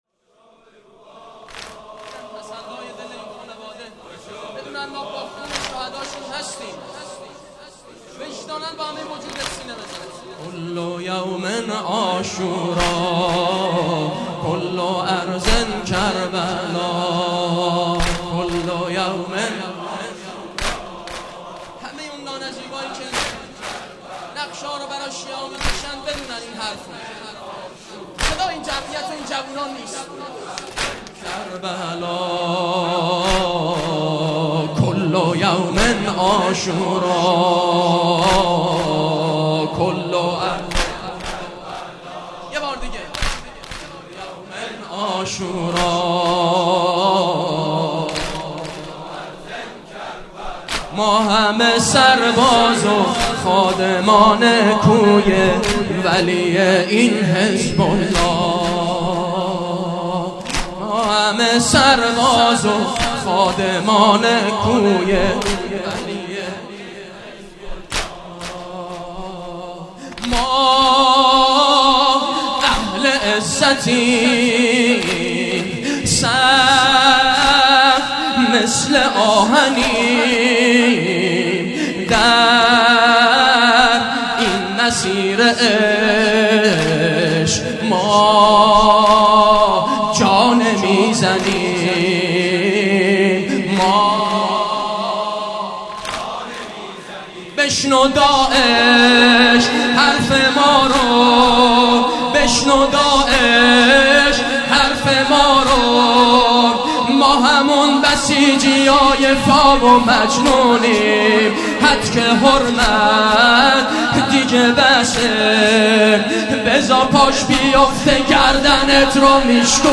واحد تند (کل یوم عاشورا، کل ارض کربلاء ...)
همه چی عالیه فقط یه انتقاد کوچولو : چرا کیفیت مداحیا ایقد پایینه ؟؟؟؟؟؟؟؟؟؟؟؟؟؟؟